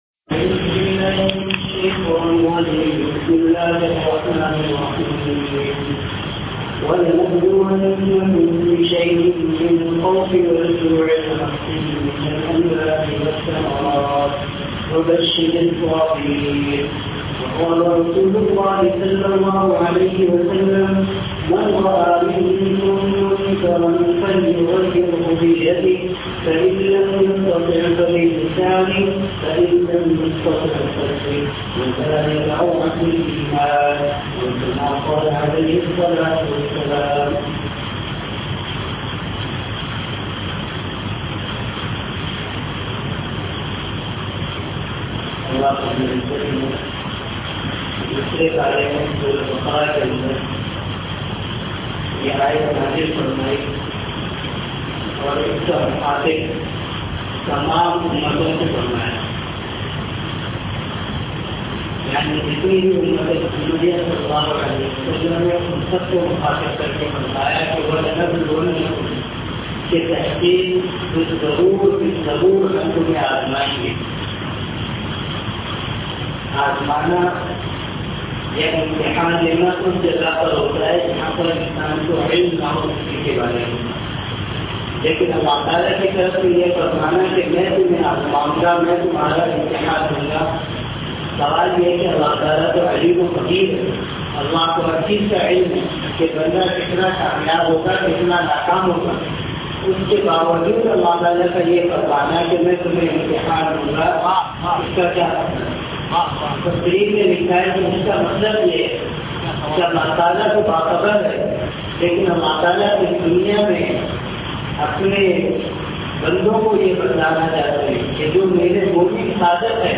Bayan : 2009-05-15 |